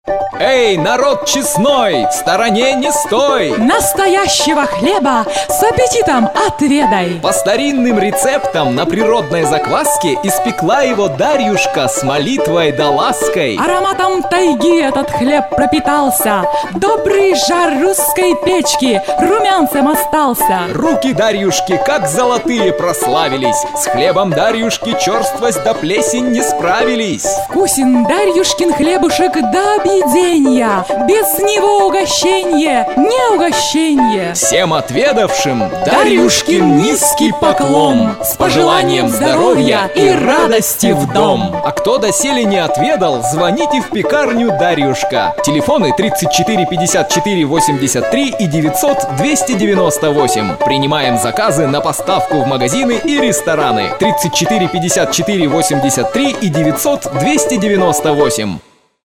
Рифмованный текст для аудиоролика (октябрь 2006)